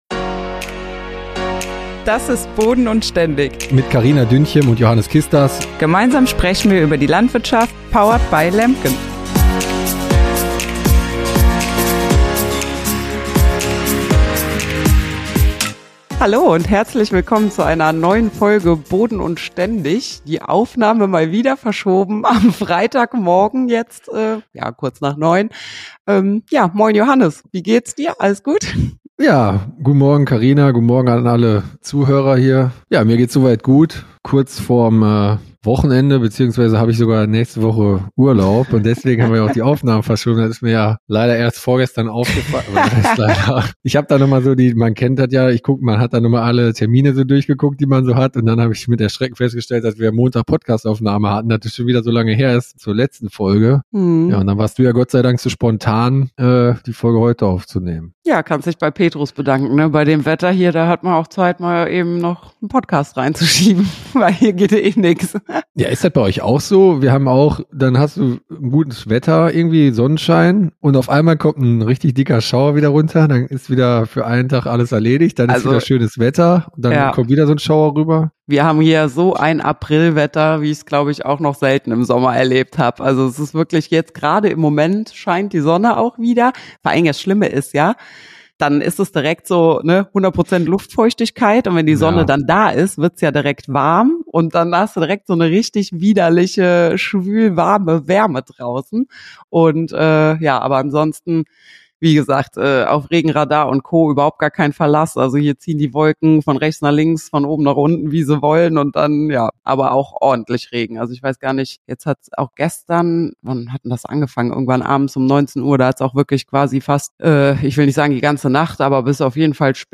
Freut euch auf jede Menge Fachwissen, Anekdoten, kleine technische Ausflüge und das typische Augenzwinkern der beiden Hosts – alles verpackt in einer lockeren, unterhaltsamen Atmosphäre.